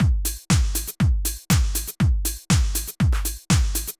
Drumloop 120bpm 03-A.wav